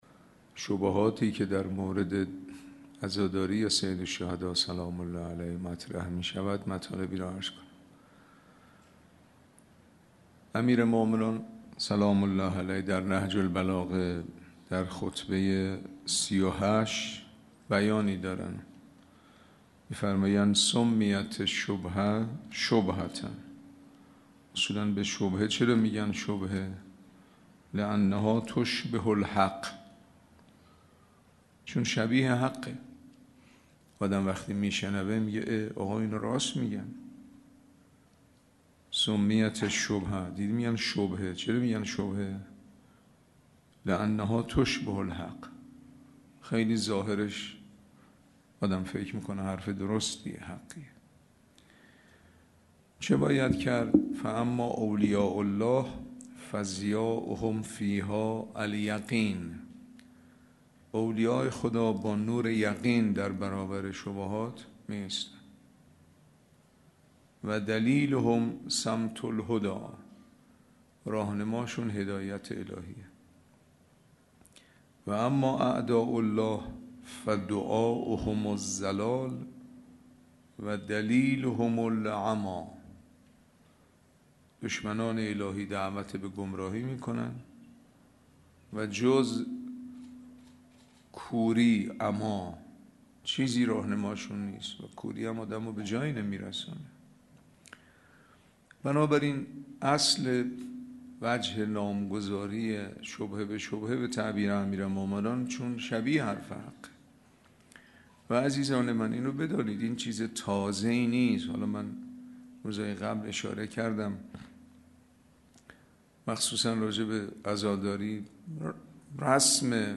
سخنرانی روز یازدهم محرم 1402